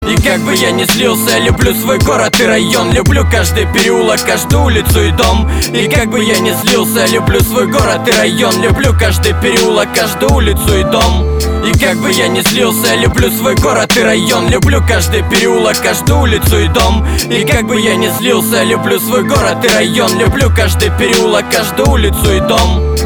• Качество: 320, Stereo
мужской голос
громкие
русский рэп
Рэп музыка, отличный припев.